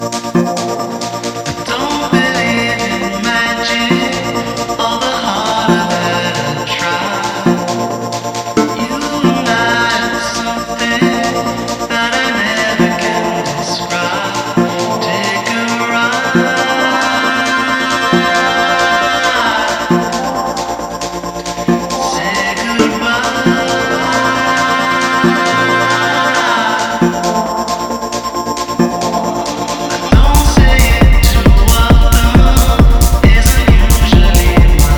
Alternative Dance
Жанр: Танцевальные / Альтернатива